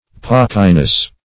Pockiness \Pock"i*ness\, n. The state of being pocky.